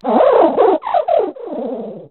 pawmot_ambient.ogg